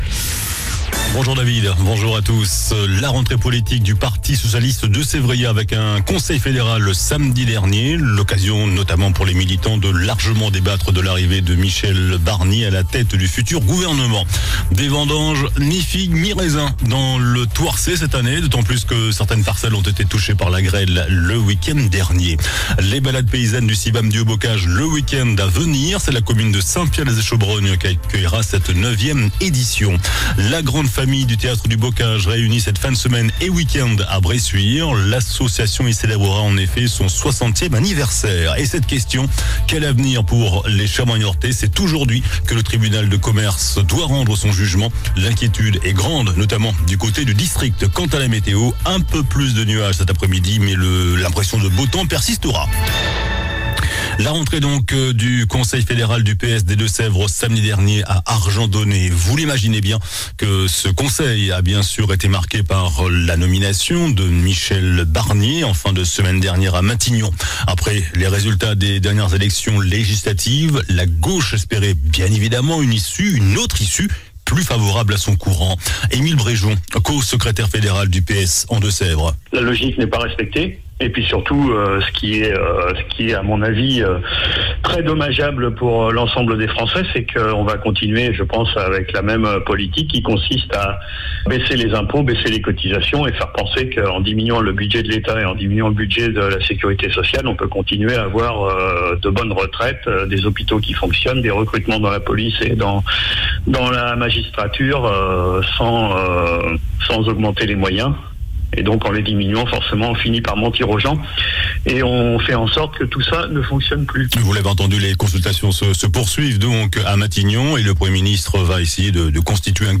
JOURNAL DU MARDI 10 SEPTEMBRE ( MIDI )